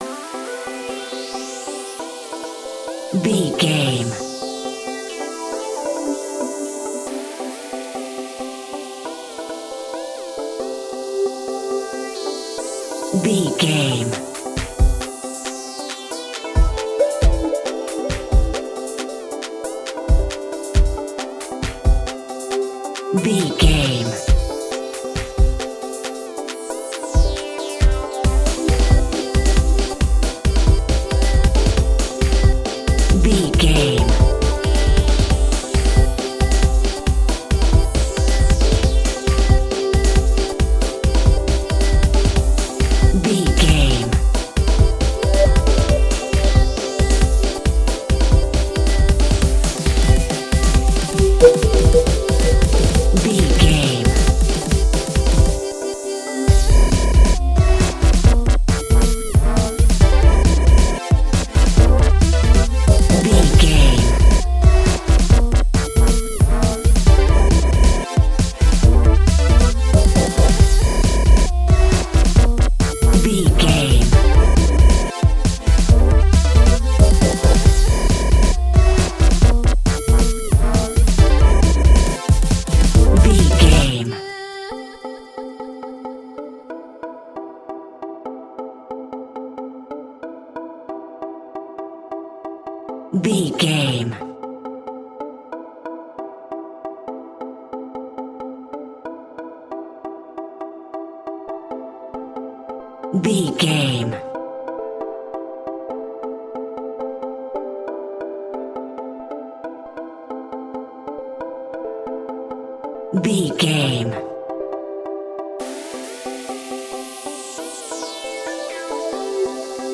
Epic / Action
Fast paced
Mixolydian
aggressive
powerful
dark
funky
groovy
futuristic
driving
energetic
drum machine
synthesiser
breakbeat
instrumentals
synth leads
synth bass